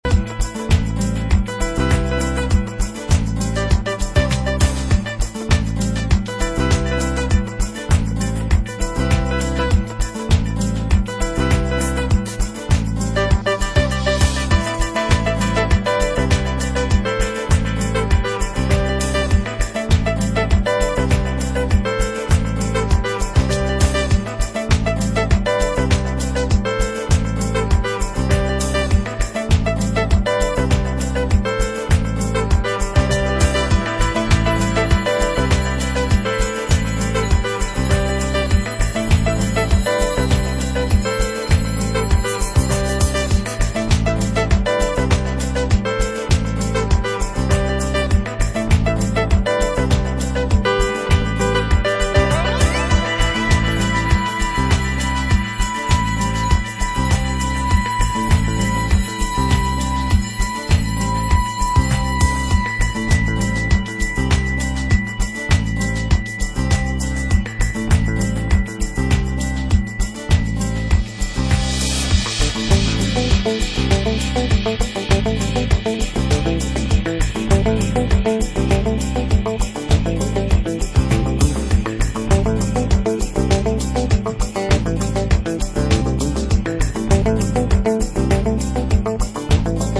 classically trained guitarist
Disco House